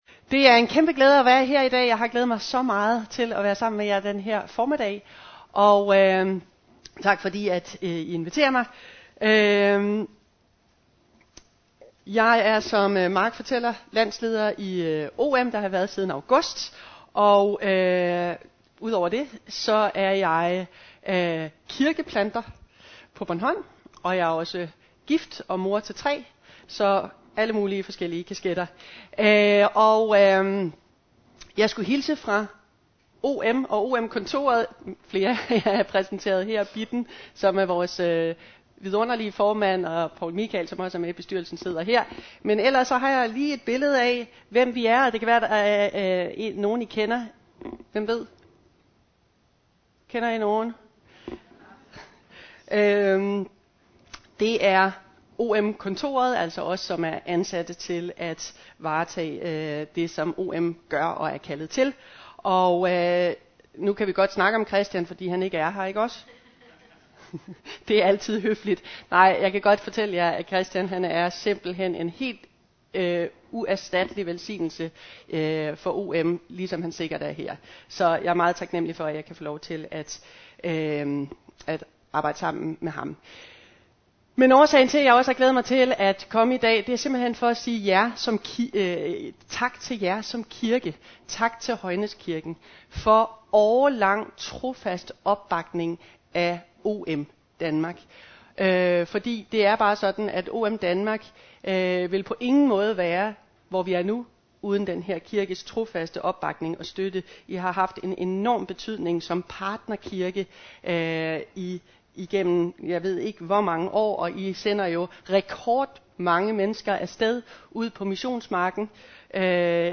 13. maj 2018 Type af tale Prædiken Bibeltekst Lukas Evangeliet MP3 Hent til egen PC